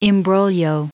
Prononciation du mot imbroglio en anglais (fichier audio)
Prononciation du mot : imbroglio